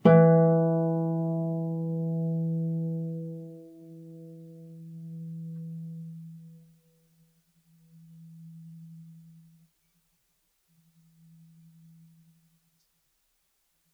KSHarp_E3_mf.wav